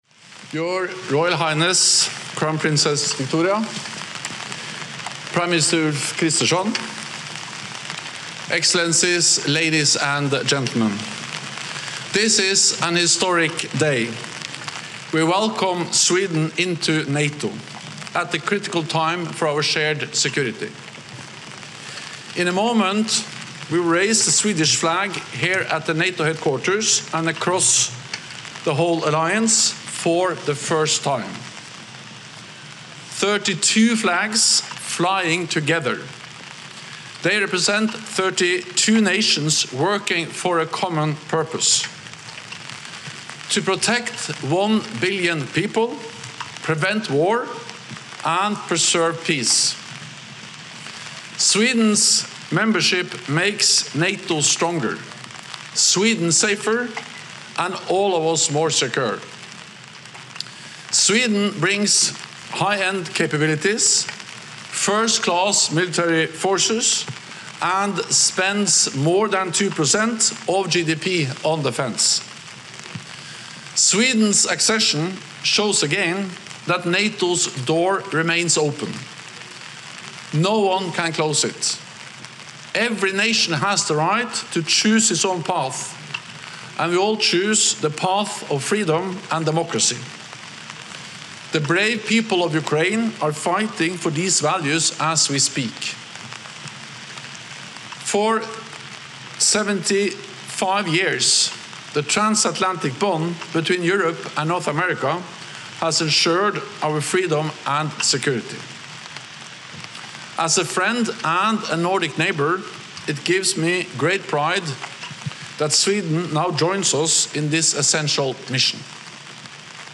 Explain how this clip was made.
Joint Remarks at Flag Raising Ceremony Signifying Sweden's Membership in NATO delivered 11 March 2024, NATO HQ, Brussels, Belgium